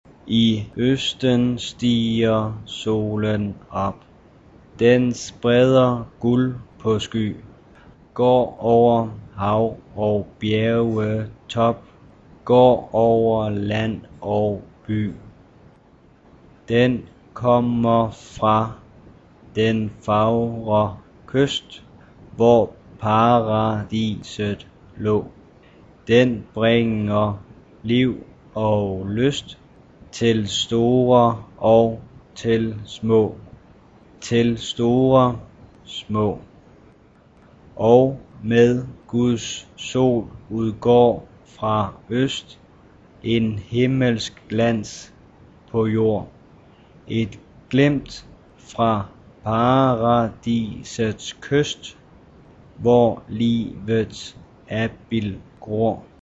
by the Danish National Radio Choir,
Type of choir: SSATTBB (7 mixed voices) + Symphony orchestra (optional)
Genre - Style - Musical FORM: Secular; Cantata chorus; hymn
Mood of the piece: Romantic; expressive
Tonality: C major